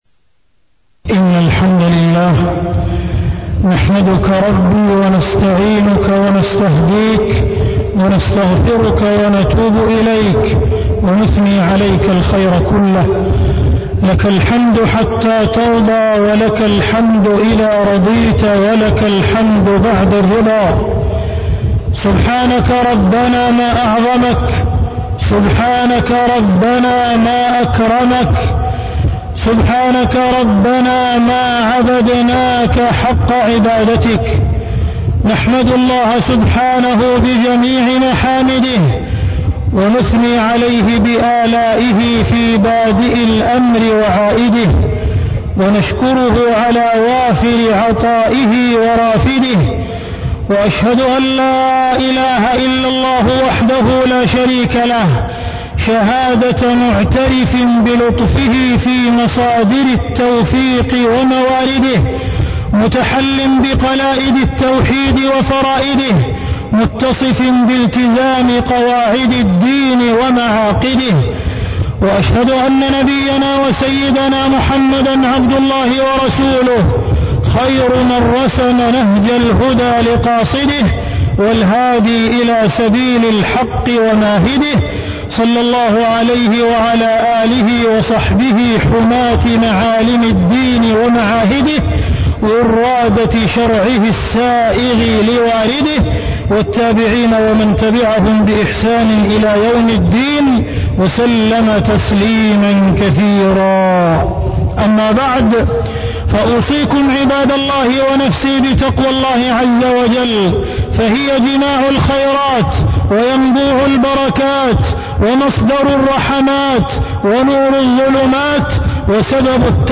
تاريخ النشر ٢٩ رجب ١٤٢٤ هـ المكان: المسجد الحرام الشيخ: معالي الشيخ أ.د. عبدالرحمن بن عبدالعزيز السديس معالي الشيخ أ.د. عبدالرحمن بن عبدالعزيز السديس الدعوة الإصلاحية وصفاء العقيدة